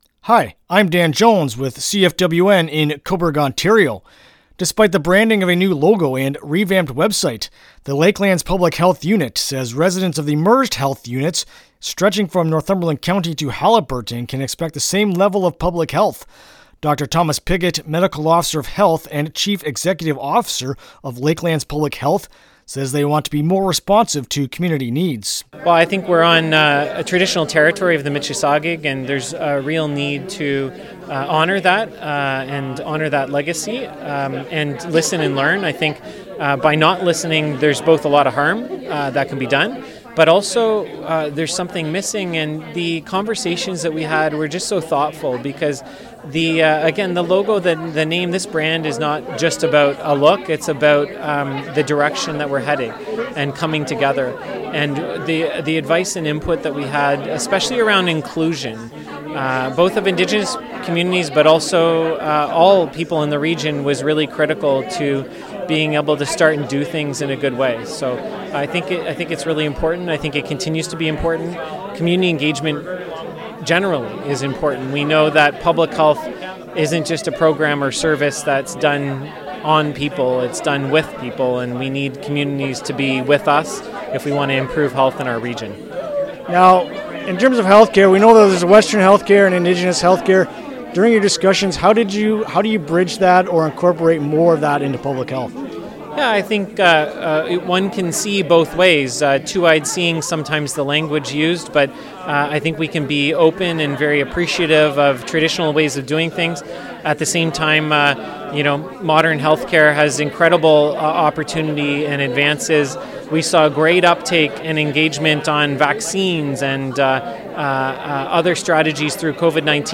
Lakeland-Health-Unit-Interview-LJI.mp3